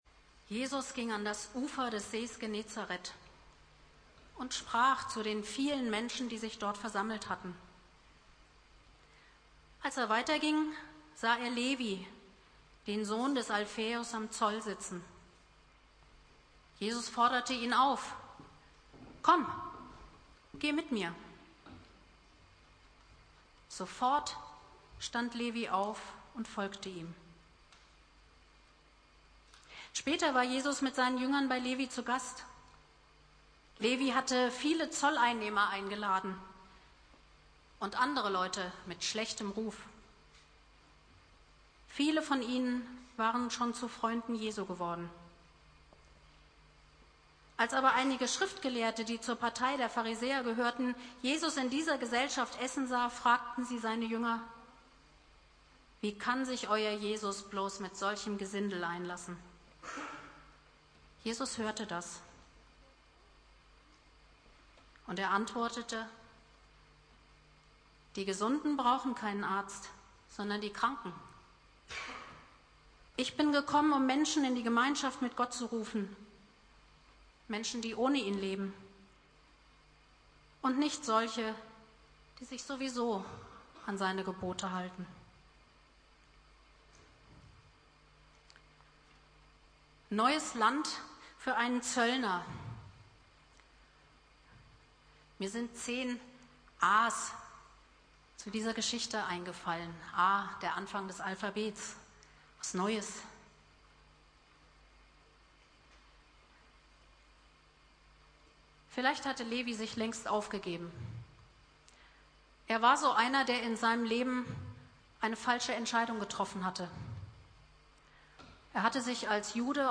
Predigt
Levi (im Bürgerhaus Hausen) Bibeltext: Markus 2,13-17 Dauer